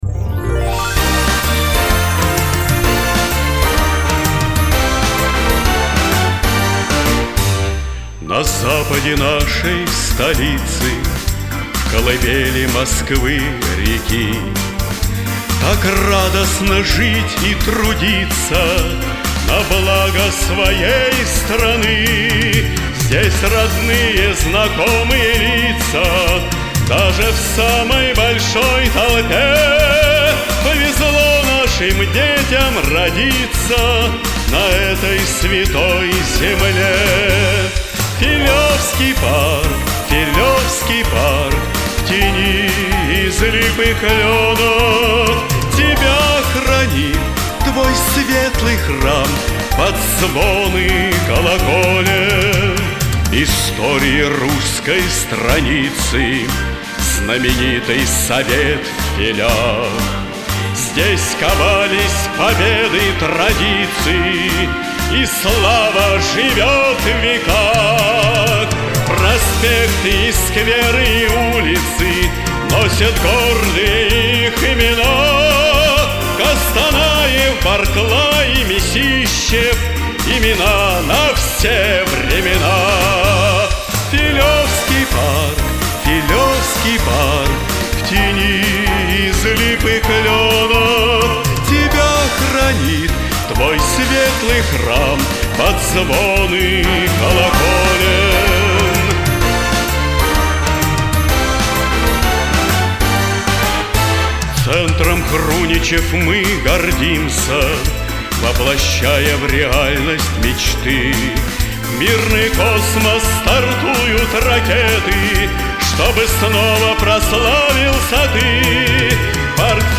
ГИМН